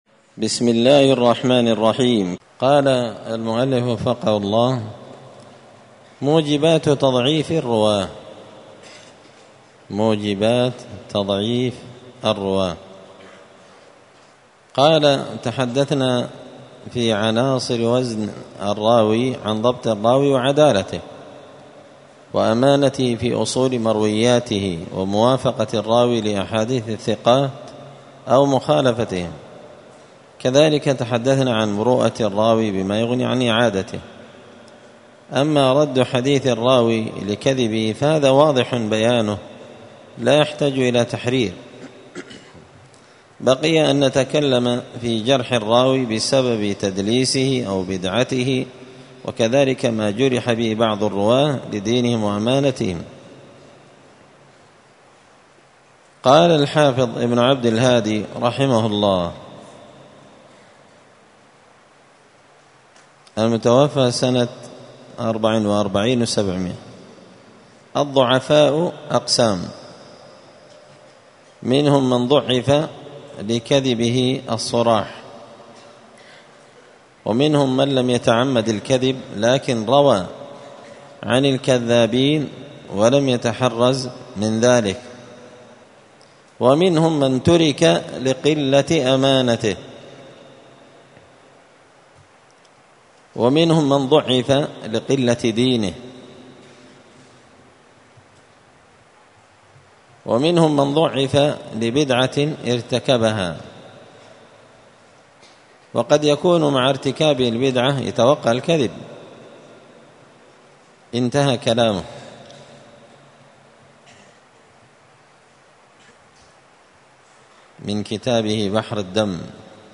*الدرس الثامن (8) موجبات تضعيف الرواة*
مسجد الفرقان قشن_المهرة_اليمن